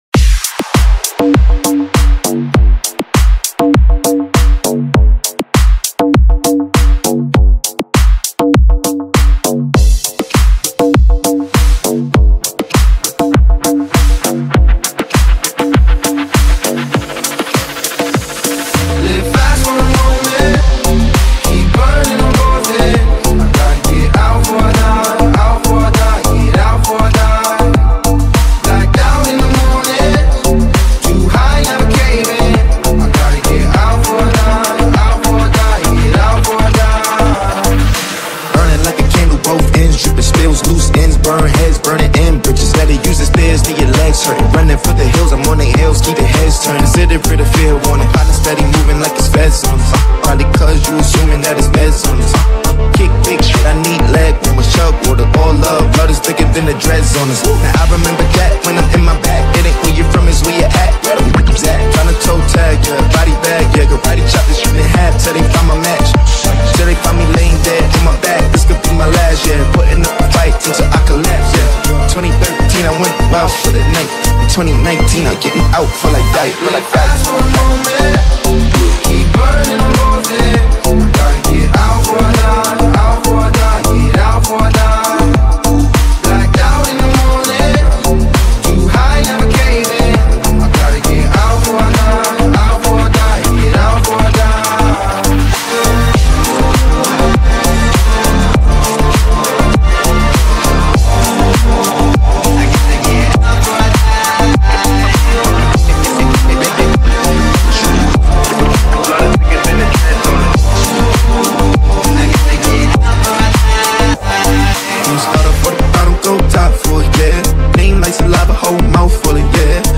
динамичную композицию в жанре EDM с элементами хип-хопа